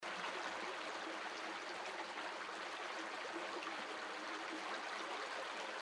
water_underbridge.mp3